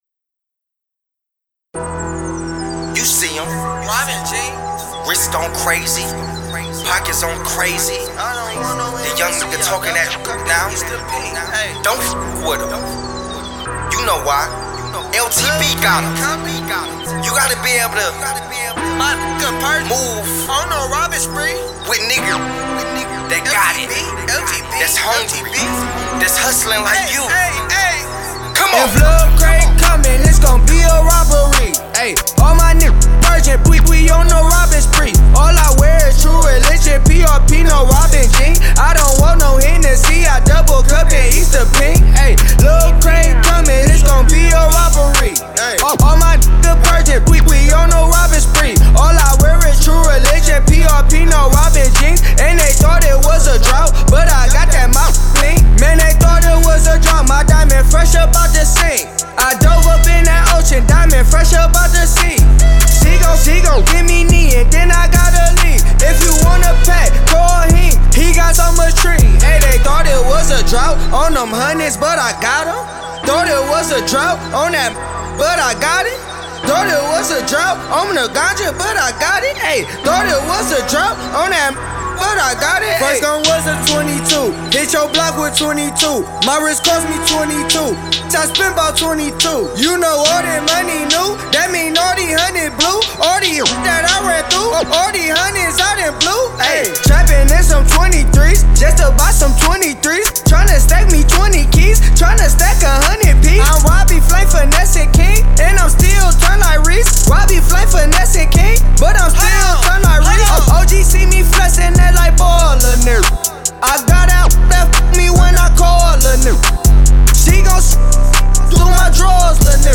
heavy street banger